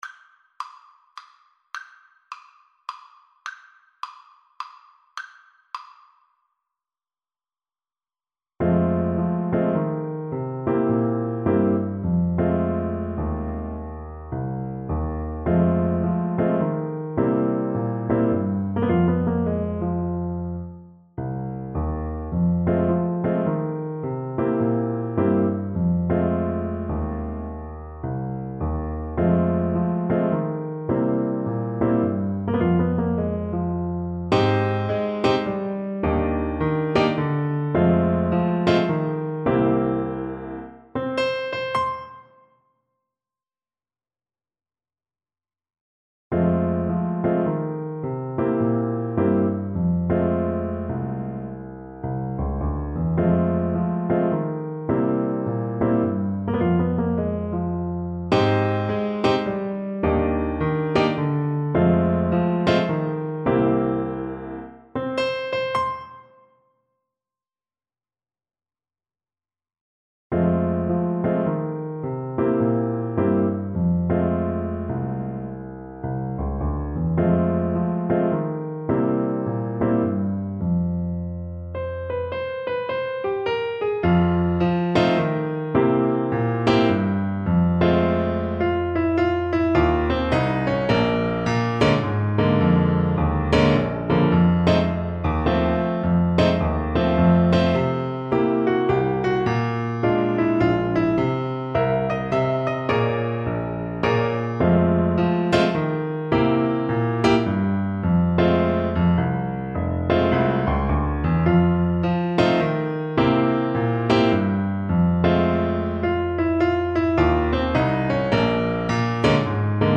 Alto Saxophone
=140 Fast swing